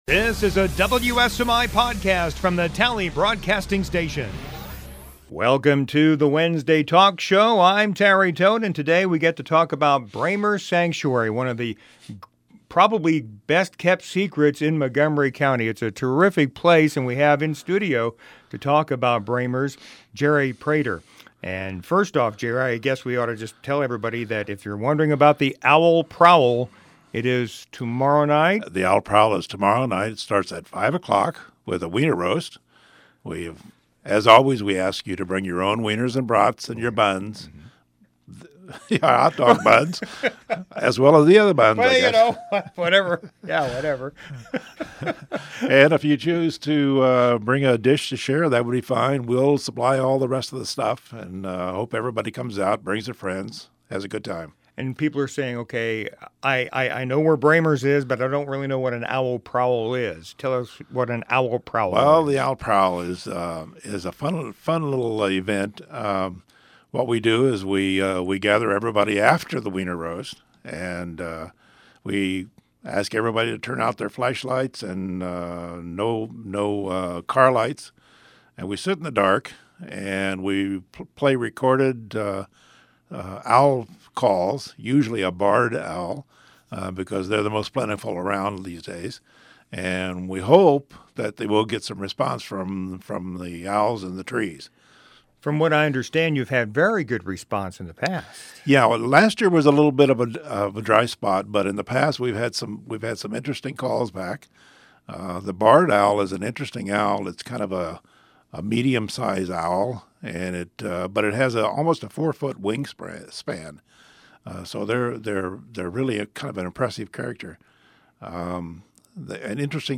Wednesday Talk Show "Owl Prowl"